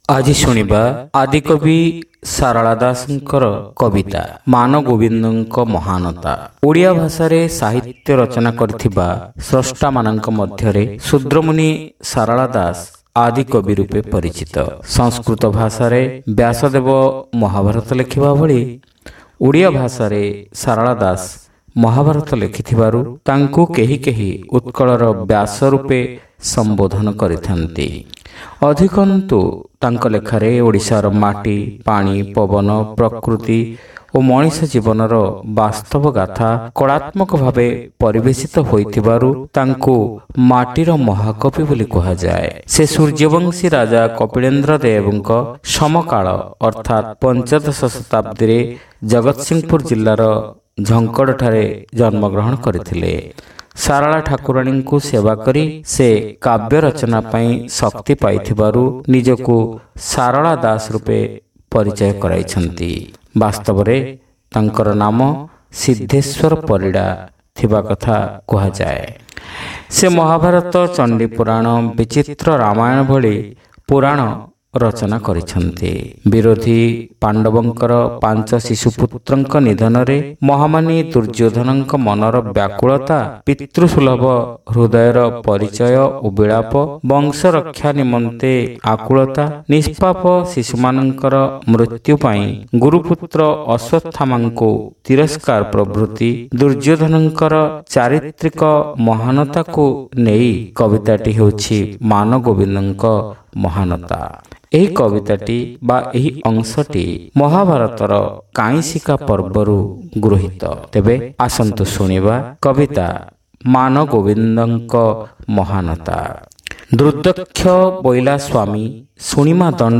ଶ୍ରାବ୍ୟ କବିତା : ମନଗୋବିନ୍ଦଙ୍କ ମହାନତା